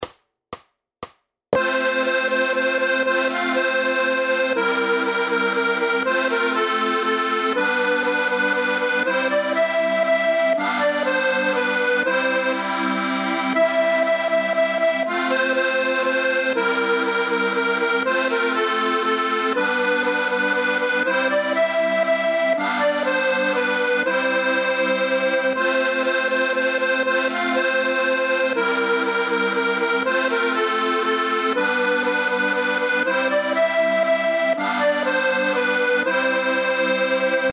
Noty na akordeon.
Hudební žánr Klasický